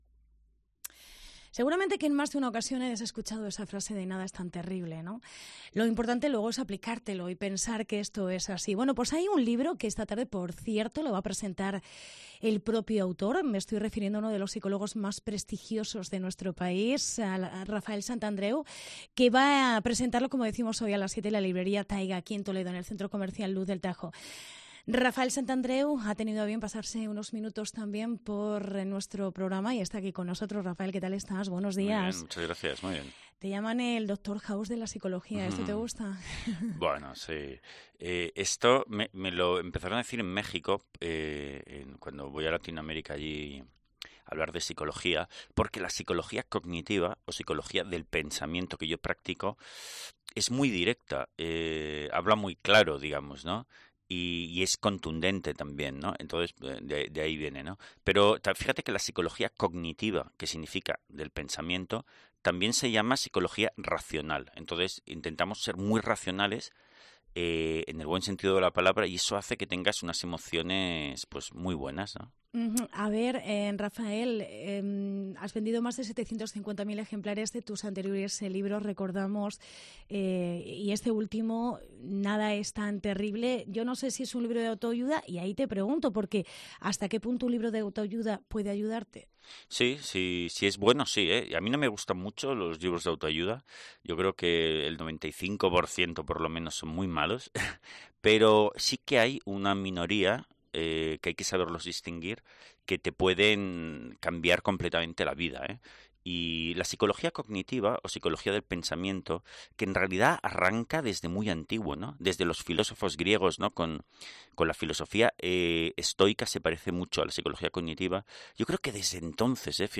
Entrevista con el prestigioso psicólogo Rafael Santandreu que hoy firma ejemplares en Toledo de su libro "Nada es tan terrible"